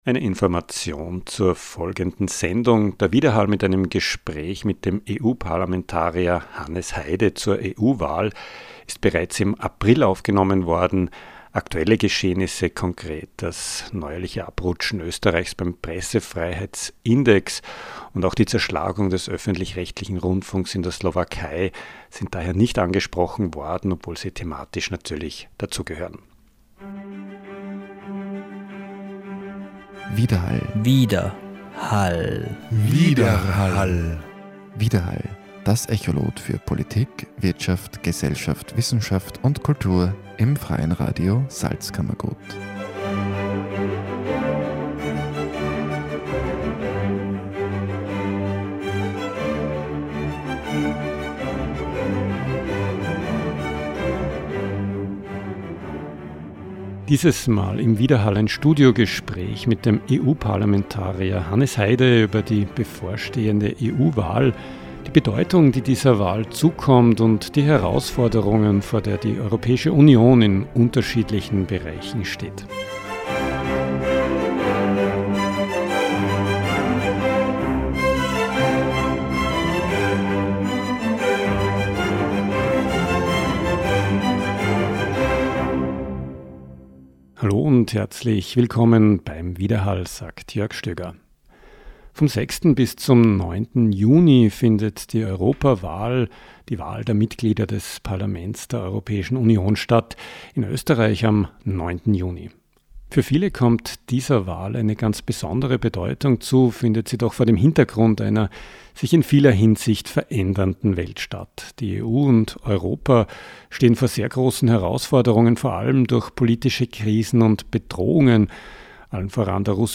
Dieses mal im Widerhall eine Studiogespräch mit dem EU Parlamentarier Hannes Heide, über die bevorstehende EU Wahl, die Bedeutung, die dieser Wahl zukommt und die Herausforderungen vor der die Europäische Union in Unterschiedlichen Bereichen steht.